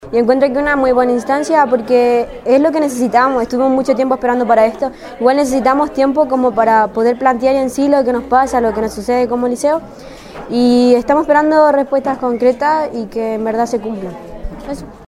Así lo comentó Javier Obanos Sandoval, Director Ejecutivo del SLEP Huasco.